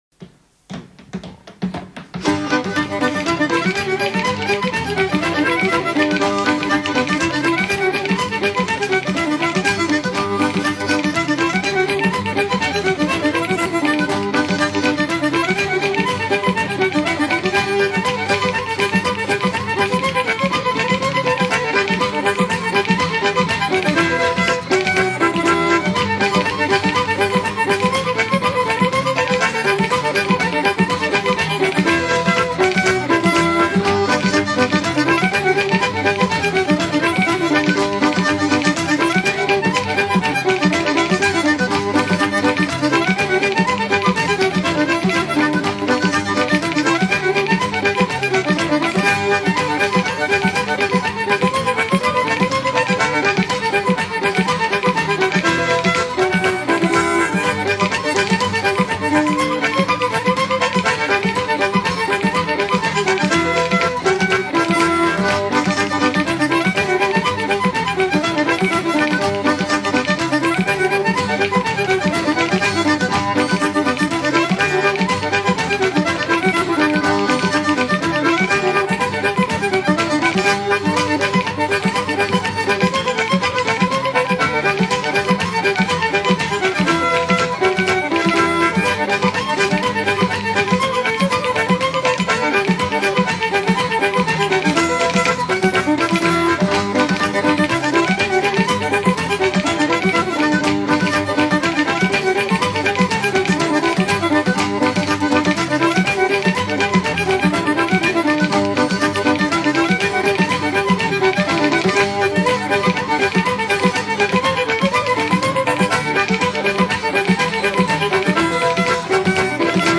danse : reel
Pièce musicale éditée